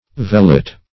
vellet - definition of vellet - synonyms, pronunciation, spelling from Free Dictionary Search Result for " vellet" : The Collaborative International Dictionary of English v.0.48: Vellet \Vel"let\, n. Velvet.